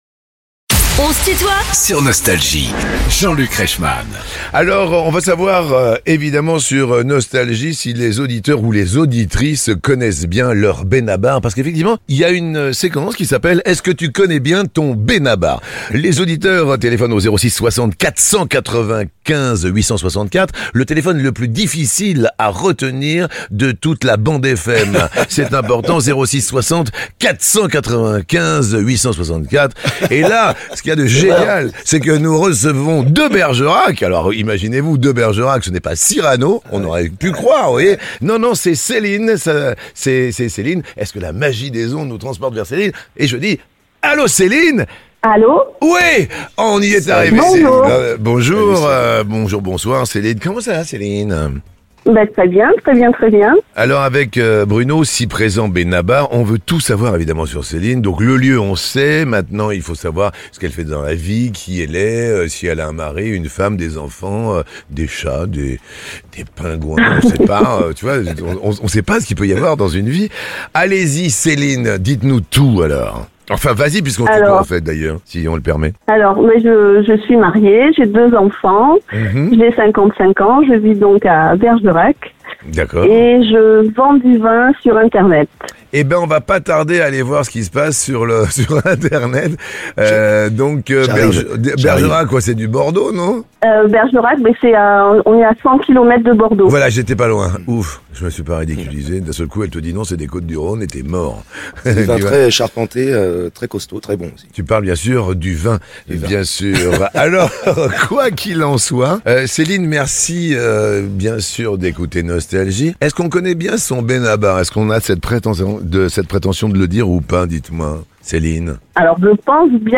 Benabar est l'invité de "On se tutoie ?..." avec Jean-Luc Reichmann